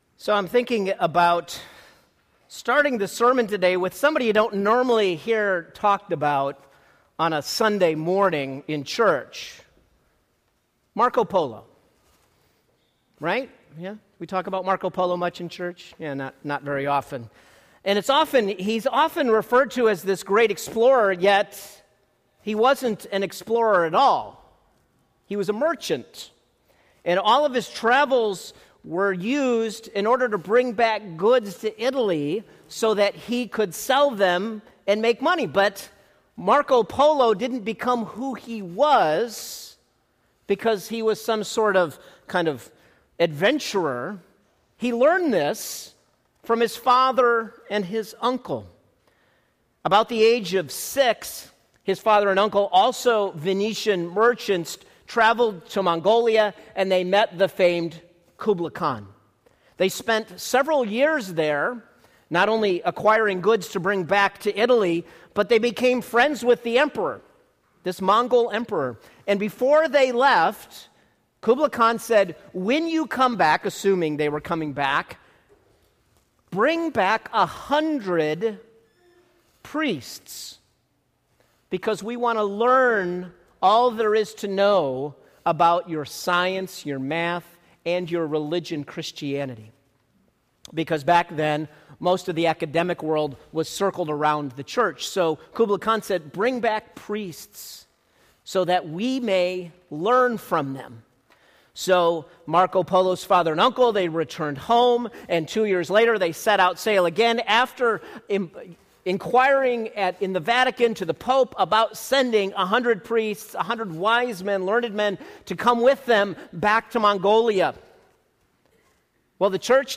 Sermon: From Marco Polo to Llamas- Being a Witness - Radical Grace For Real People
Sermon-From-Marco-Polo-to-Llamas.mp3